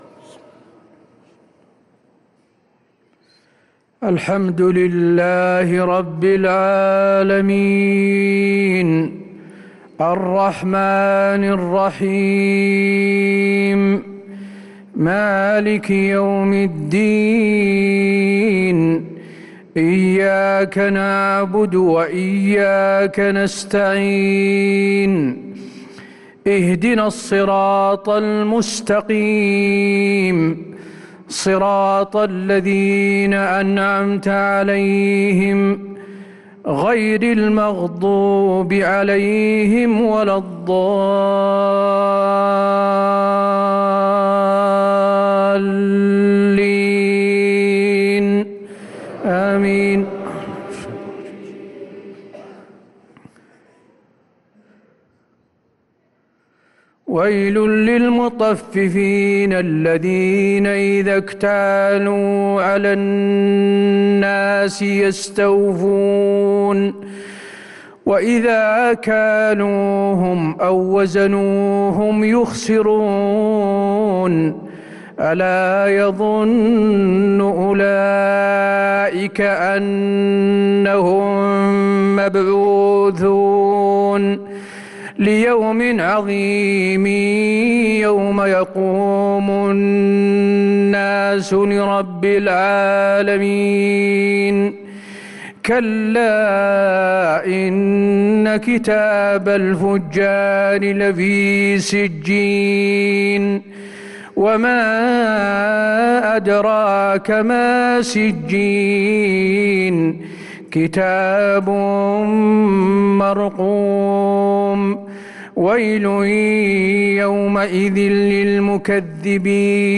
عشاء الأحد 8-2-1444هـ من سورة المطففين 1-28 | Isha prayer from Surat Al-Mutaffifin 4-9-2022 > 1444 🕌 > الفروض - تلاوات الحرمين